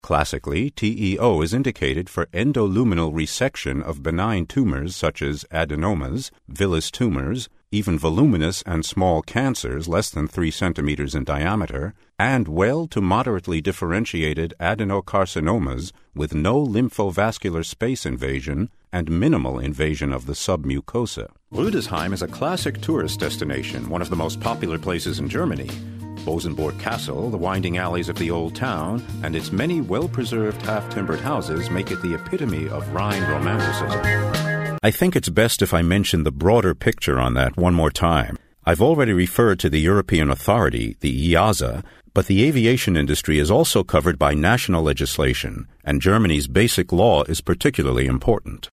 Native English Speaker; bass voice; technical, medical or documentary narration; excellent actor; short-notice access to studios
mid-atlantic
Sprechprobe: eLearning (Muttersprache):